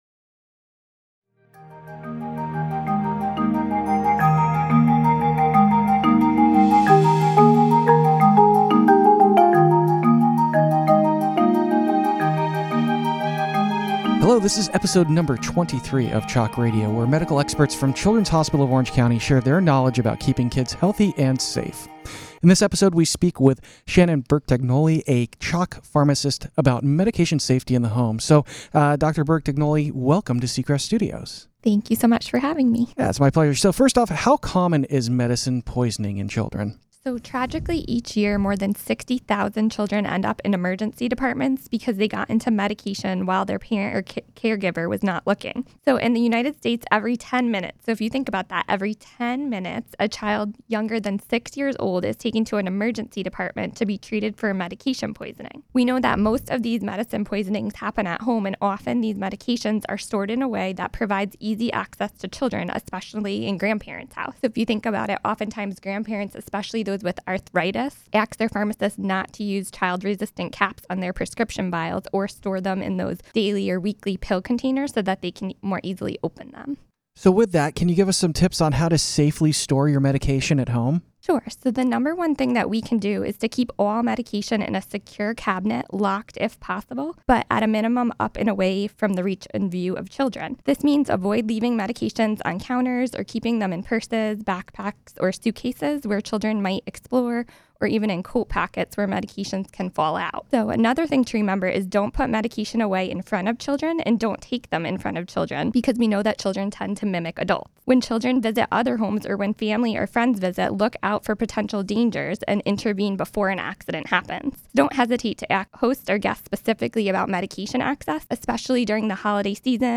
CHOC Radio theme music